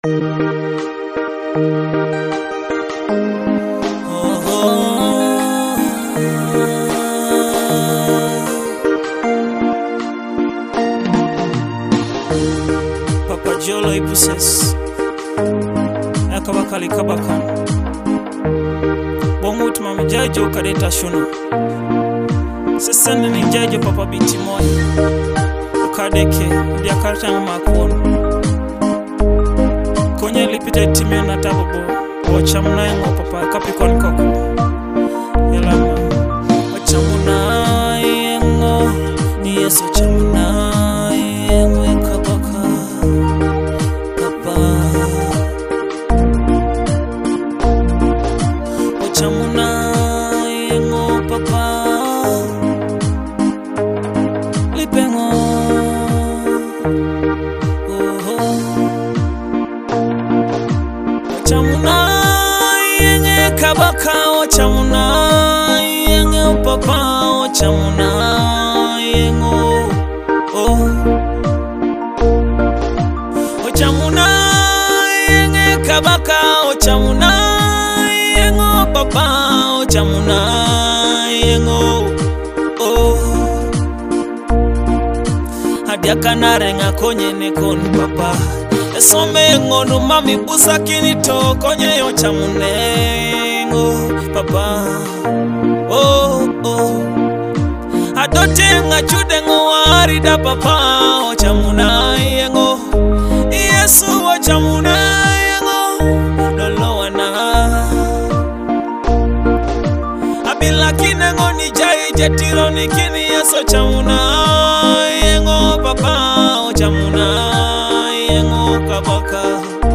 Soul-stirring vocals and uplifting rhythms.